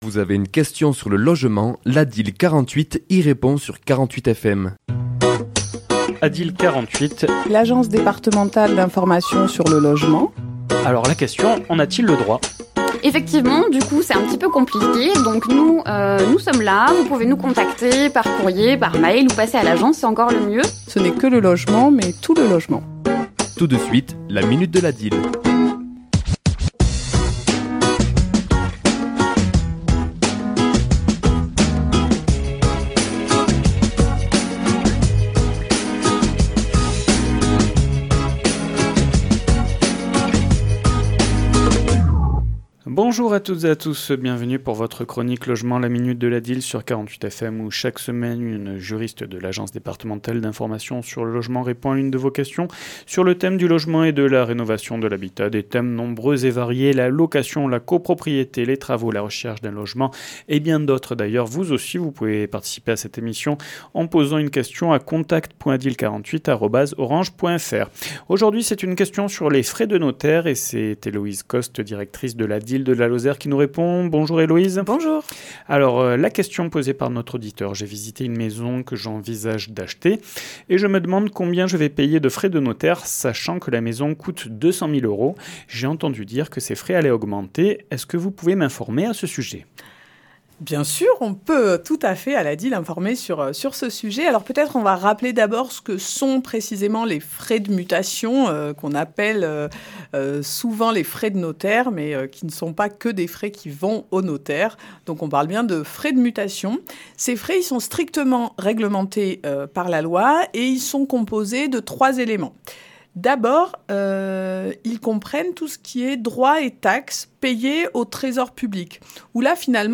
Chronique diffusée le mardi 25 février à 11h et 17h10